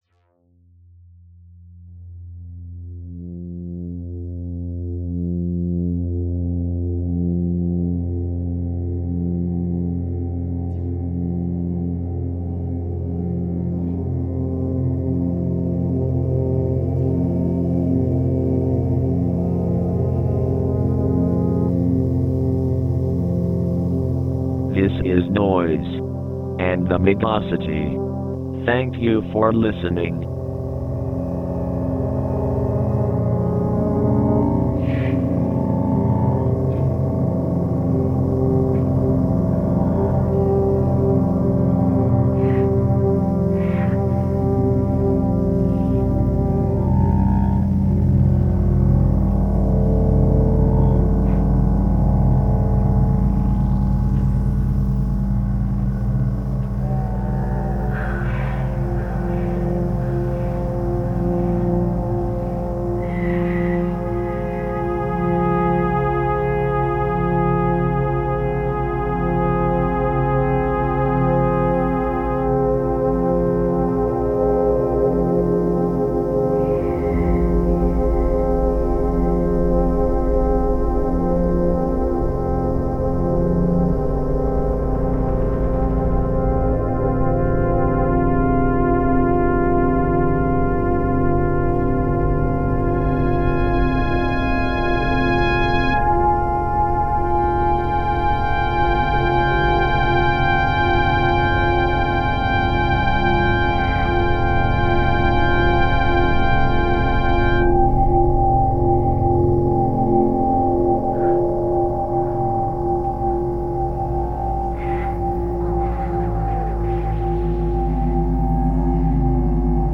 along with some thrift store CD mashups